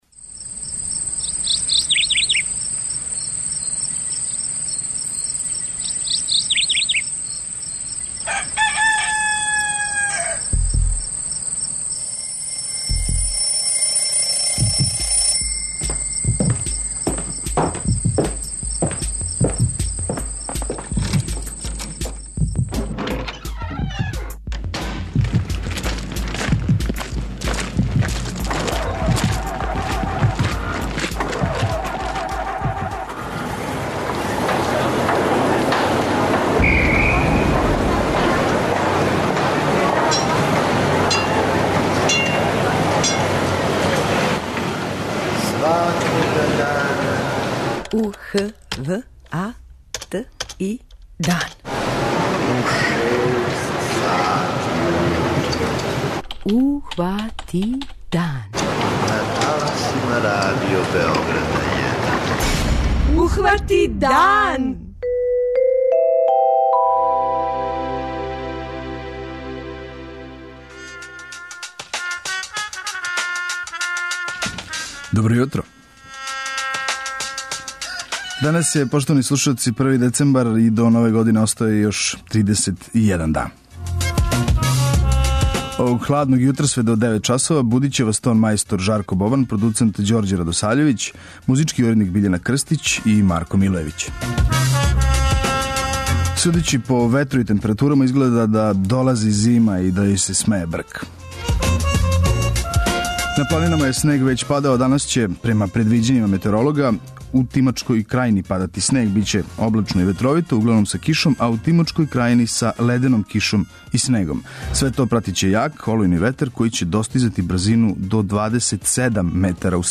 У оквиру емисије емитујемо: 06:03 Јутарњи дневник; 06:35 Догодило се на данашњи дан; 07:00 Вести; 07:05 Добро јутро децо; 08:00 Вести; 08:10 Српски на српском
О задацима и раду свратишта разговарали смо са извршном директорком те организације.